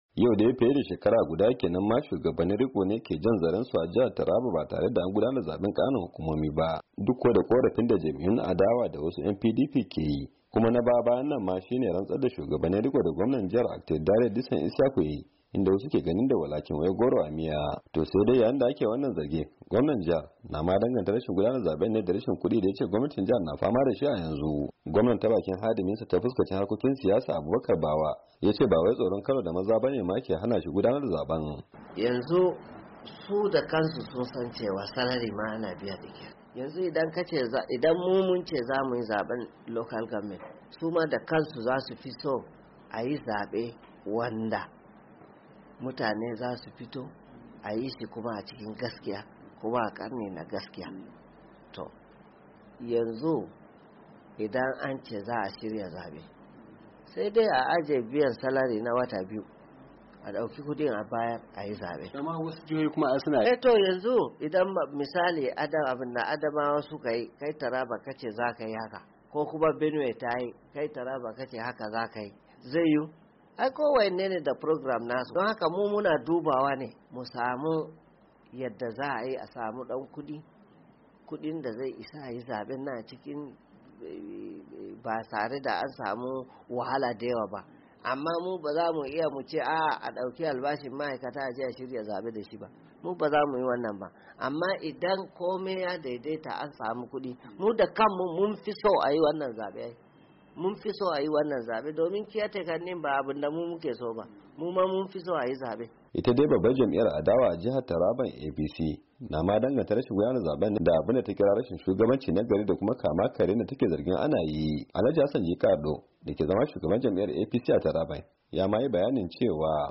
rahoton